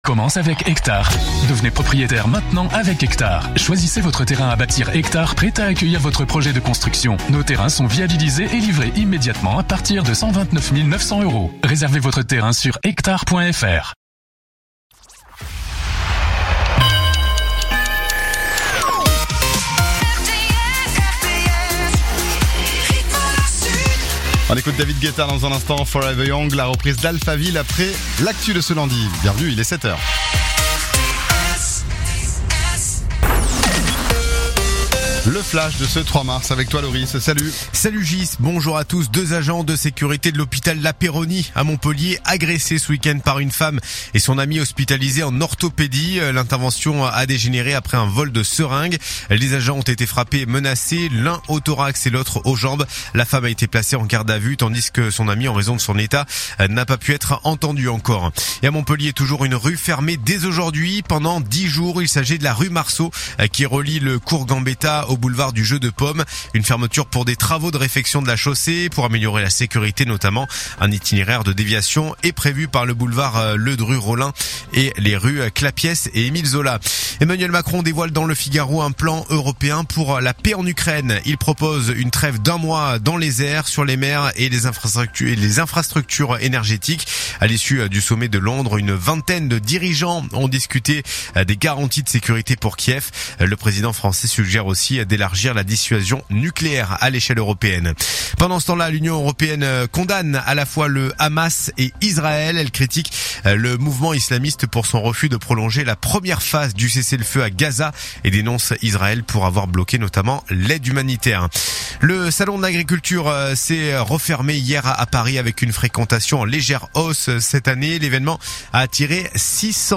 Écoutez les dernières actus de l'Hérault en 3 min : faits divers, économie, politique, sport, météo. 7h,7h30,8h,8h30,9h,17h,18h,19h.